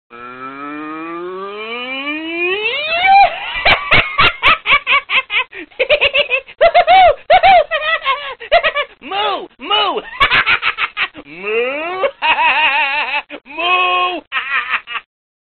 نغمة بصوت بقرة مضحكة جدا